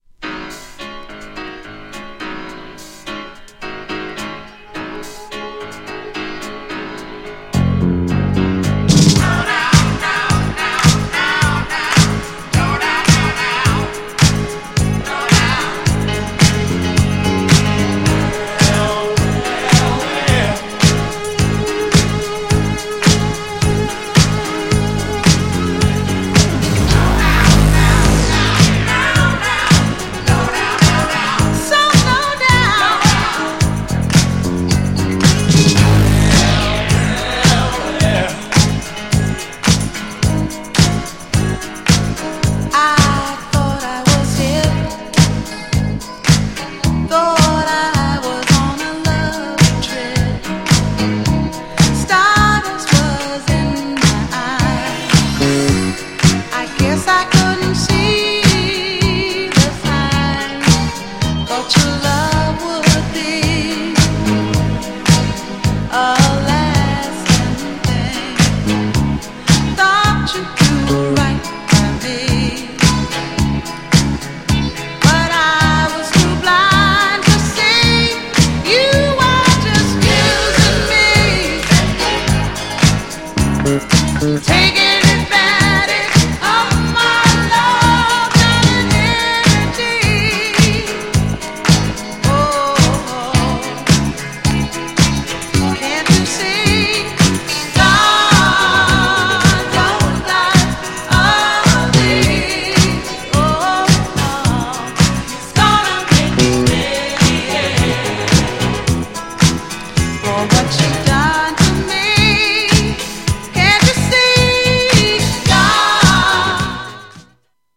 GENRE Dance Classic
BPM 111〜115BPM